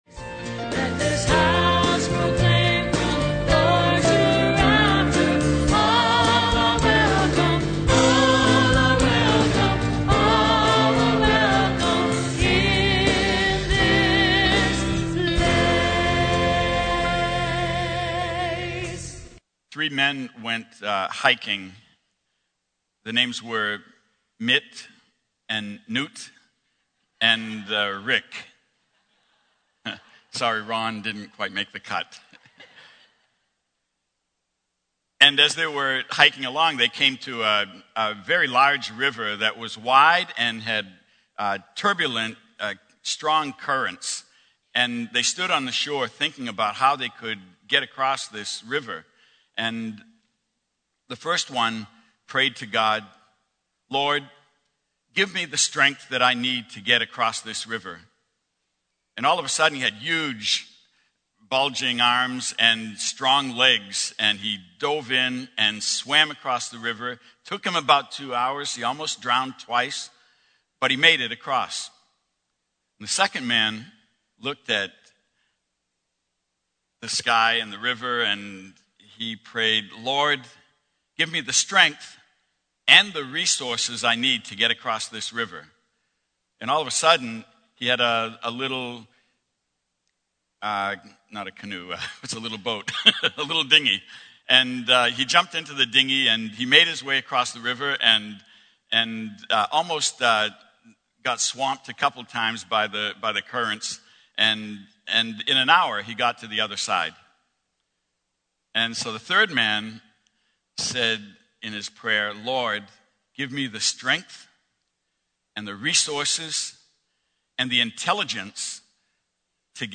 Homily - 1/22/12 - 3rd Sunday Ordinary Time